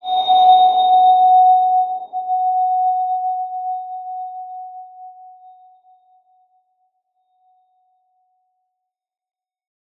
X_BasicBells-F#3-ff.wav